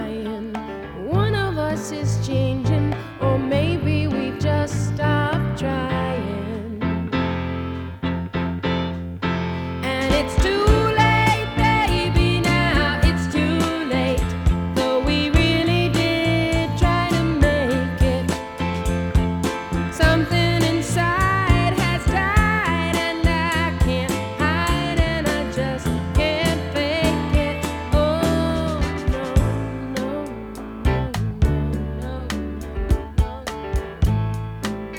Soft Rock
Жанр: Поп музыка / Рок